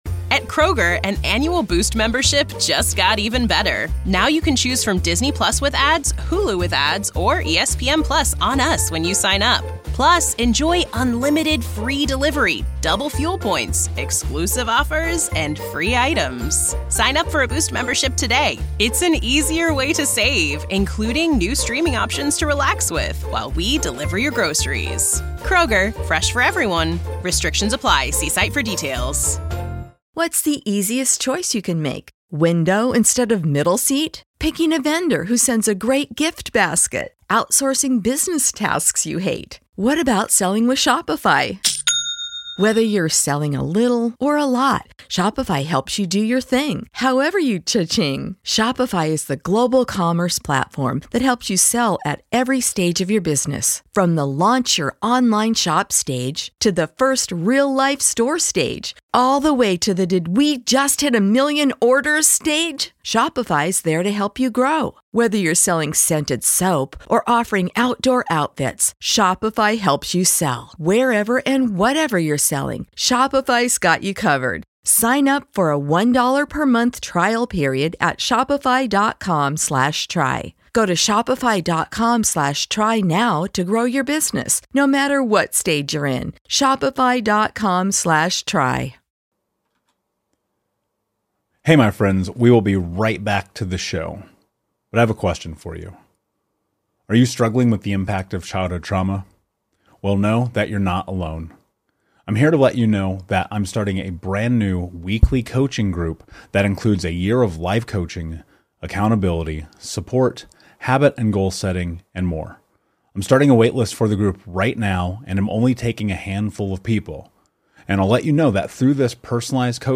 This conversation is heartfelt, honest, vulnerable, and beautiful.